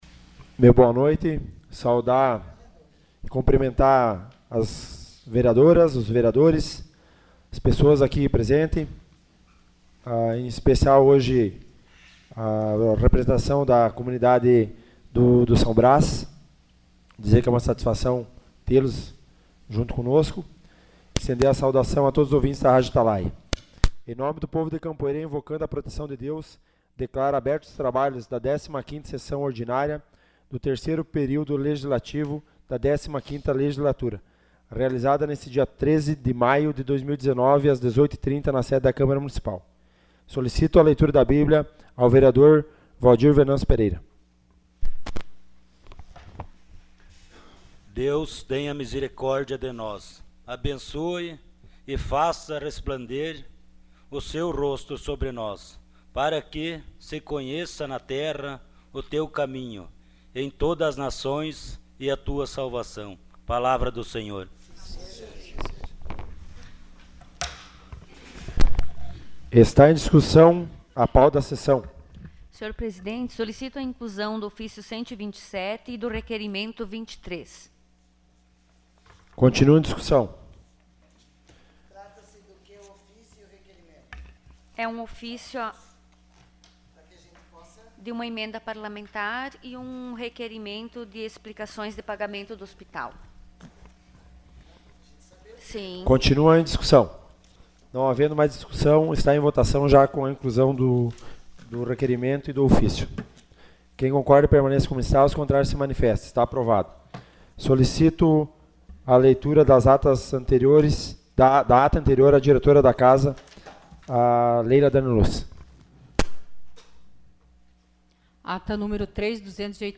Sessão Ordinária dia 23 de maio de 2019.